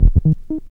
03SYN.LICK.wav